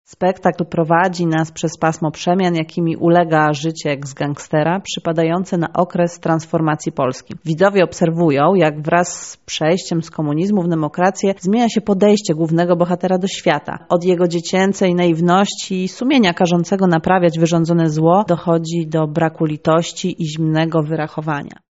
Zapowiedź spektakli w CK